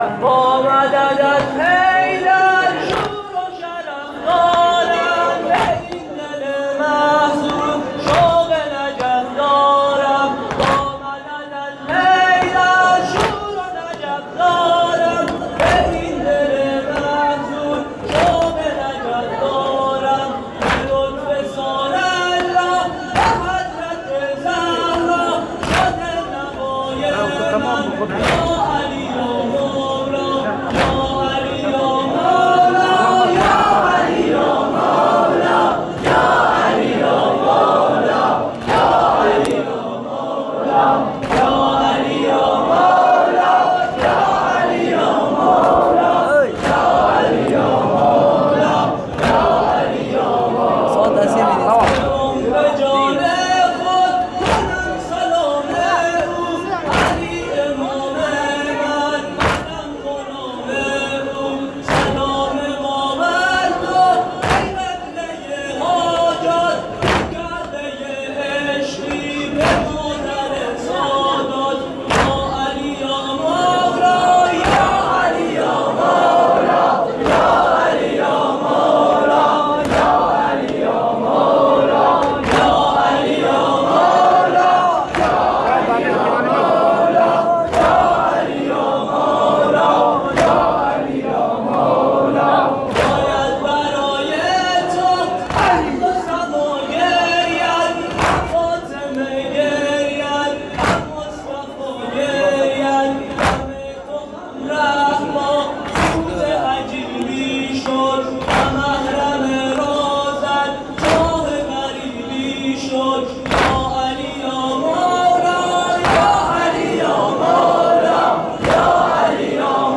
مسجد حضرت ابوالفضل (ع)
شب دوم قدر (21رمضان) 1440